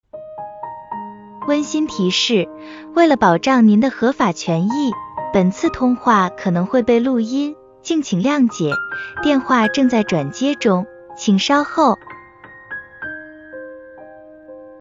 IVR音频录制试听案例：